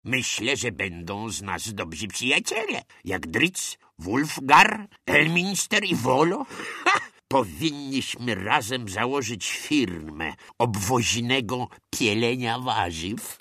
Jeden z zabawnych komentarzy Jana Jansena (w tej roli doskonały Jan Kobuszewski)
Specjalnie zamieściłem plik MP3 z małym przykładem komentarza jednego z bohaterów, którego możemy przyłączyć do drużyny.